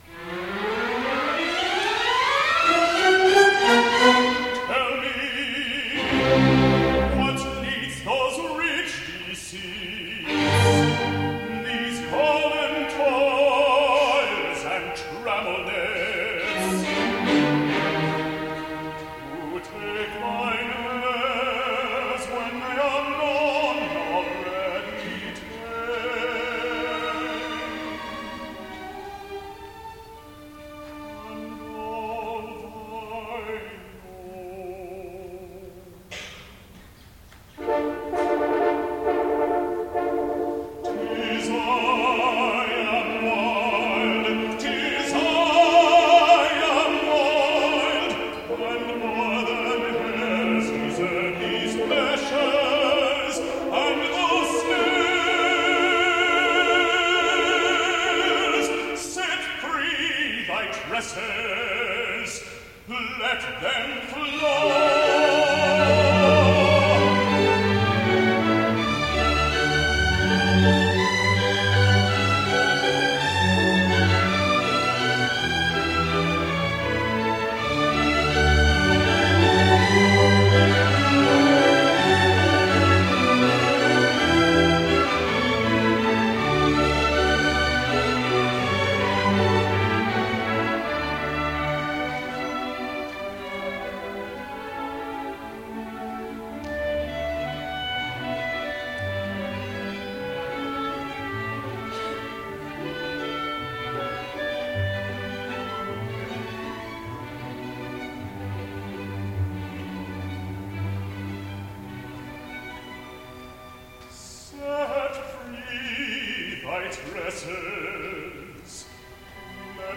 for Soprano, Baritone, Chorus and Orchestra